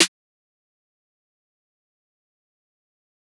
Snare [ No Heart ].wav